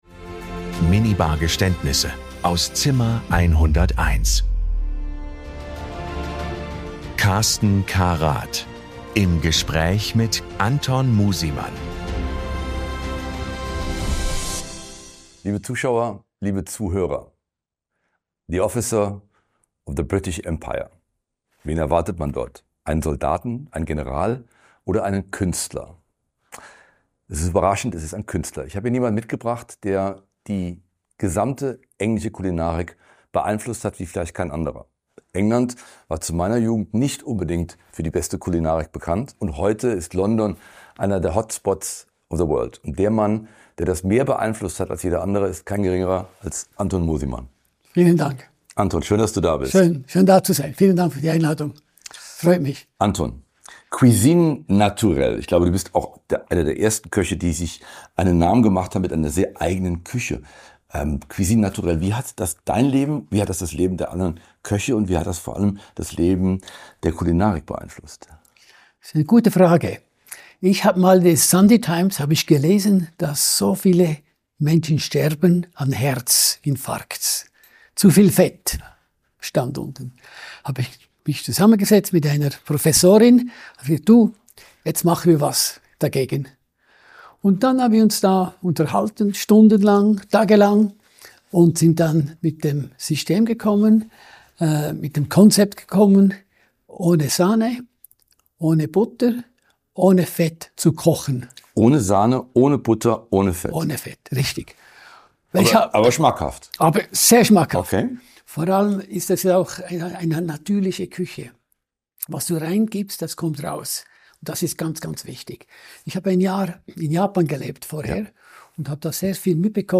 Es wird gelacht, reflektiert und manchmal auch gestanden.
Ein Gespräch über Präzision, Disziplin und ein Leben im Dienst des Geschmacks.